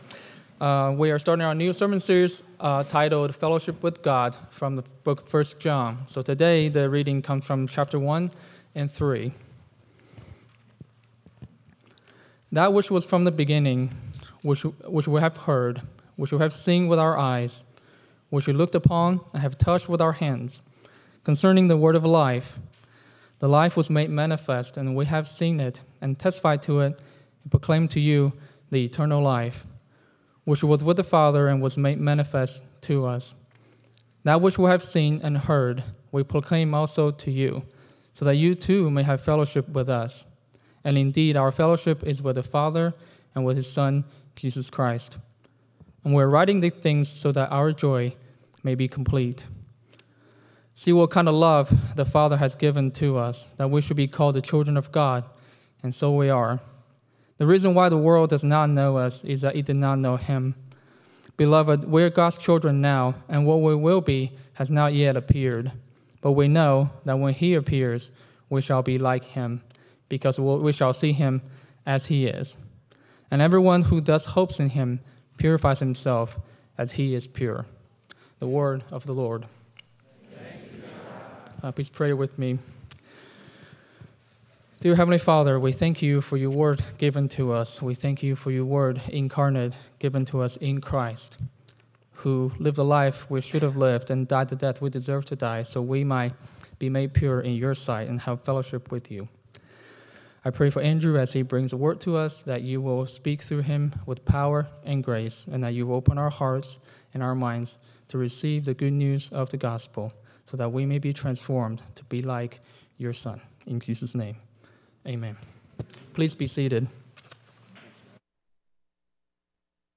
9.7.25 Sermon.m4a